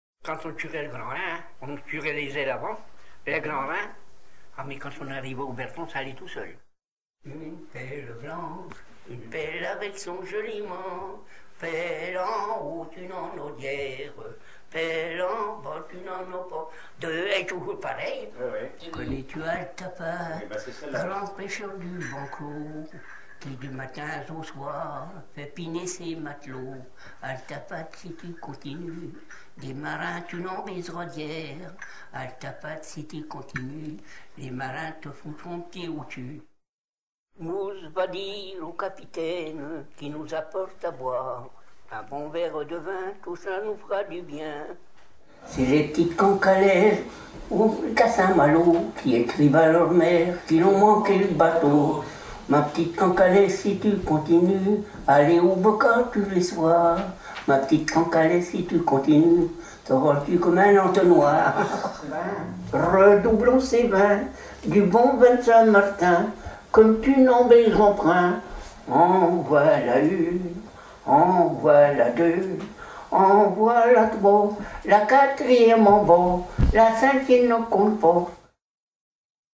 enregistrement du terre-neuvier
Haleurs ou dameurs travaillant ensemble rythmiquement